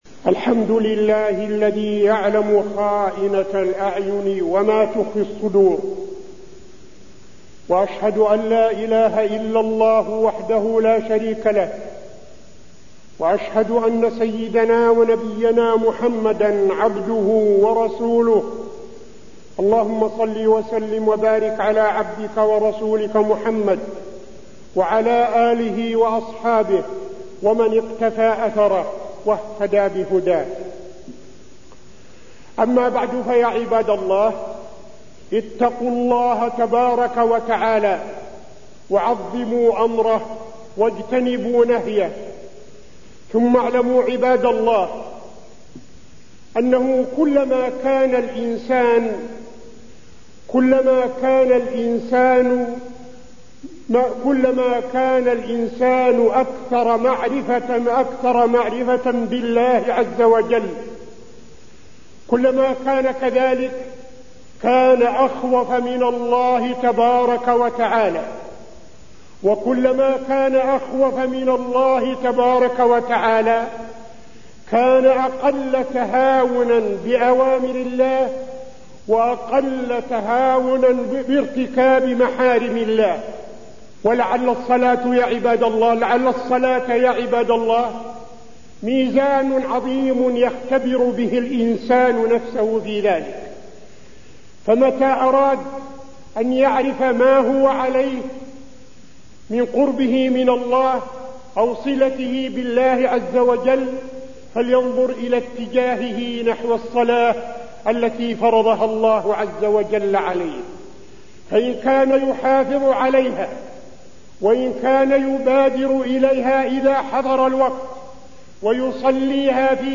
تاريخ النشر ١٩ ربيع الأول ١٤٠٤ هـ المكان: المسجد النبوي الشيخ: فضيلة الشيخ عبدالعزيز بن صالح فضيلة الشيخ عبدالعزيز بن صالح الصلاة وأهميتها